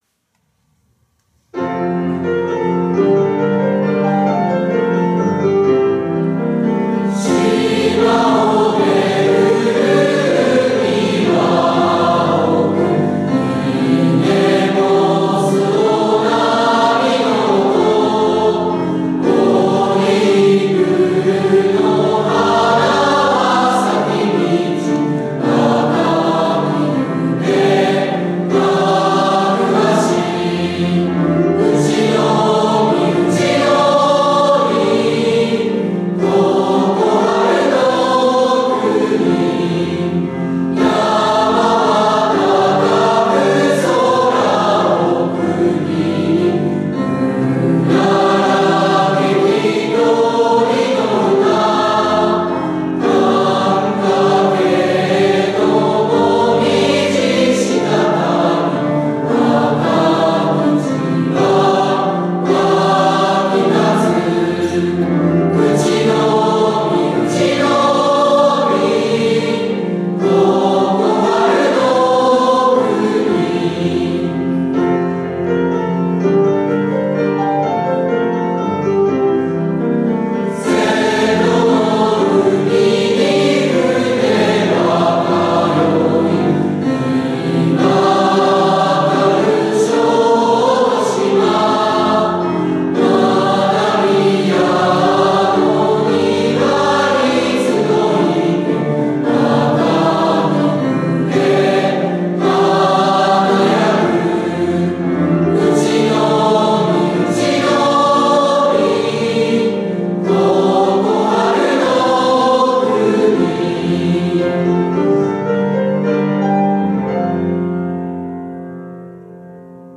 校歌が決定しました